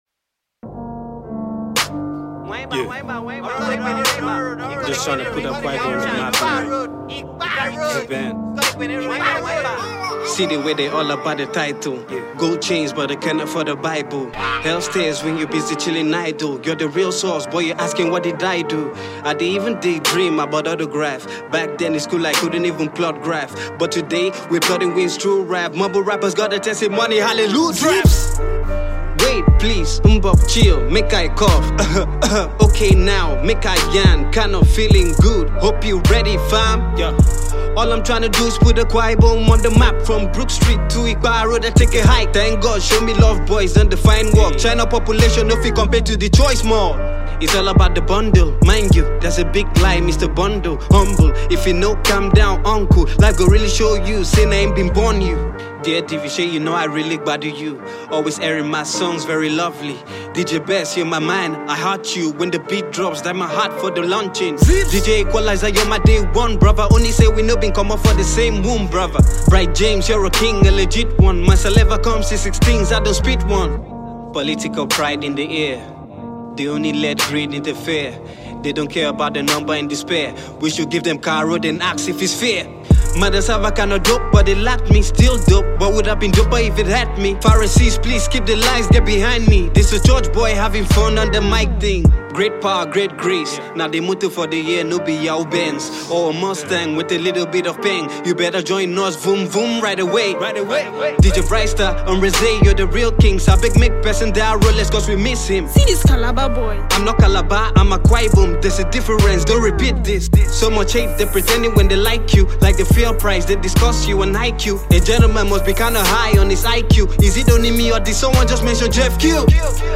dishes out some scintillating verses on this one